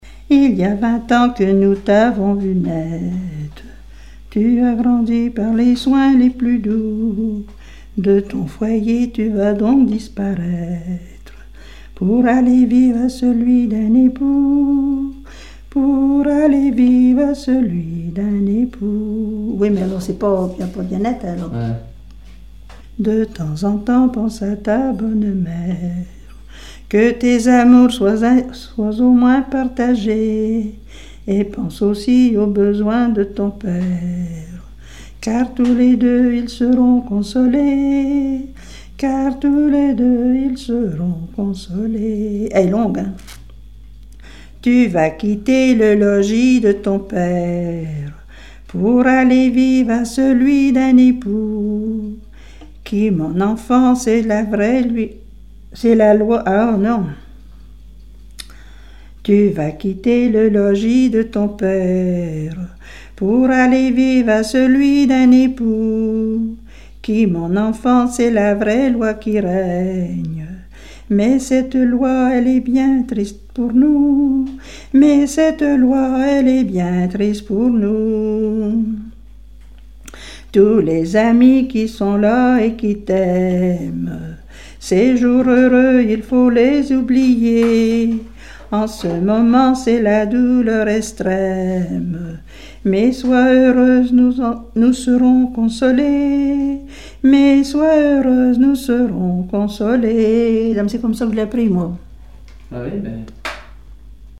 Chanson de la mère de la mariée
Genre strophique
Pièce musicale inédite